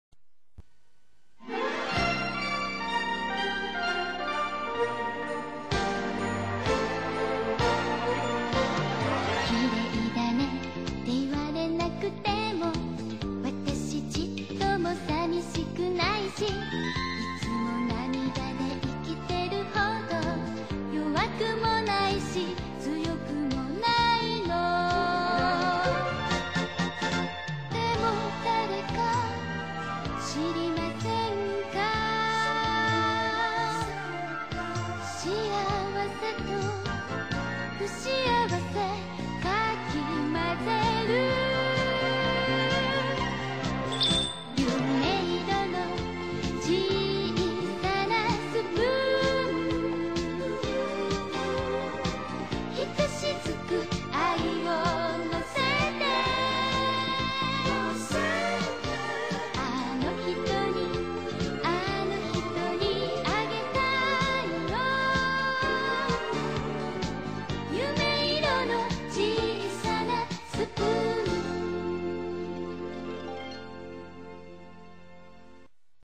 موسیقی تیتراژ